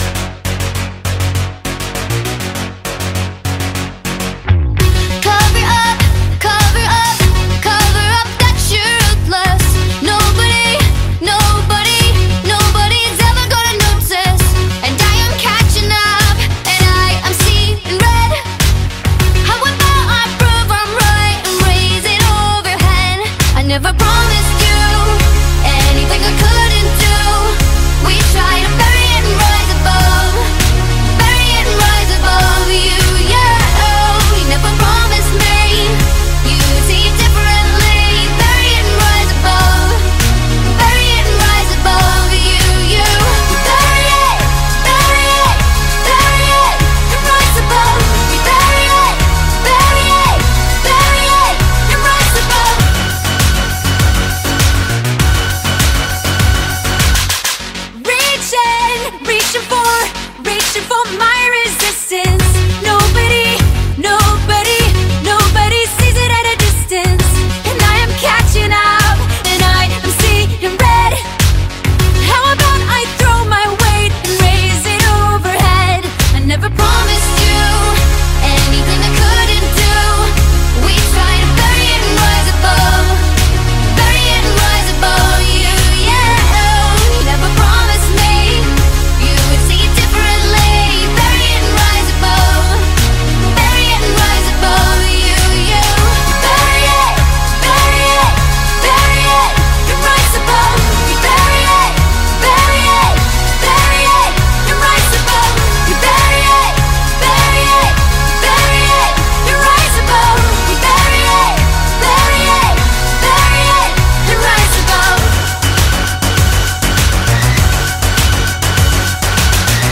BPM100
synthpop